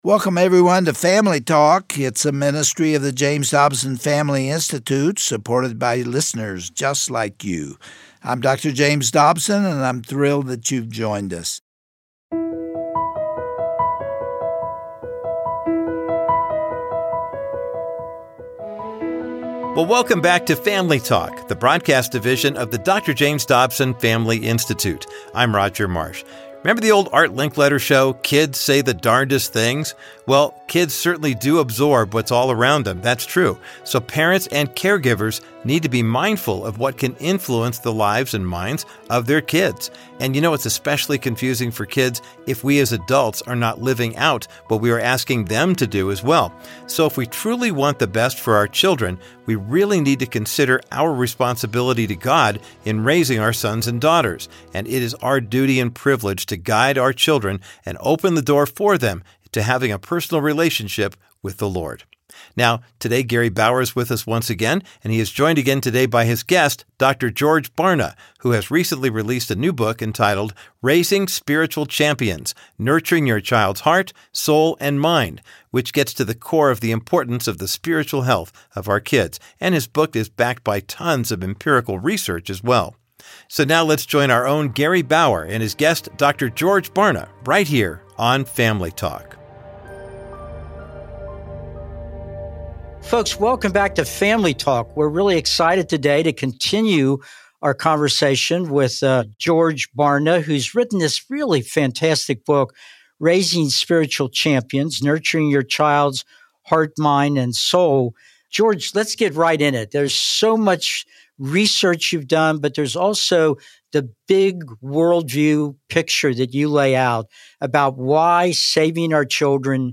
On today’s edition of Family Talk, Gary Bauer concludes his passionate discussion with Dr. George Barna about his book, Raising Spiritual Champions: Nurturing Your Child’s Heart, Mind and Soul. If we, as parents, do not fulfill our God-given privilege of guiding the development of our children, based on the Christian faith, we will leave them to the mercy of a fallen world.